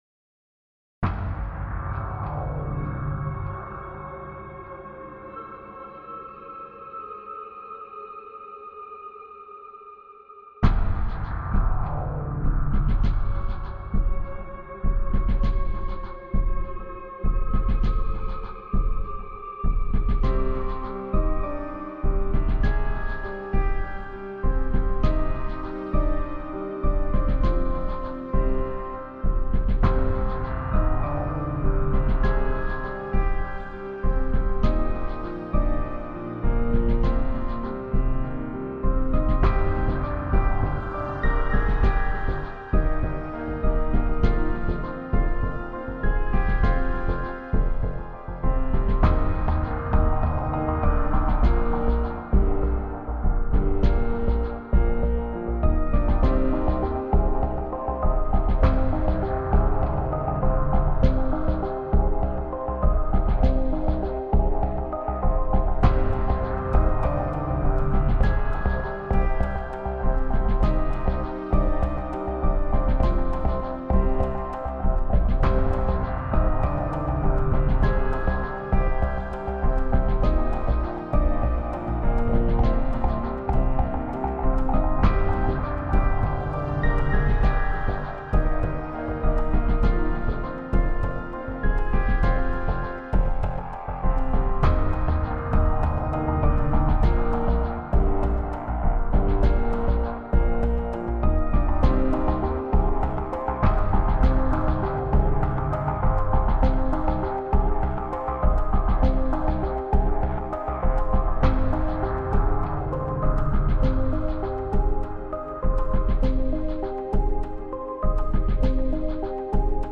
The piece is what we term ‘a naked demo’ in that there were no external sound effects ormixing enhancements used whatsoever , so the sounds are completely unprocessed aside from Omnisphere’s own internal effects.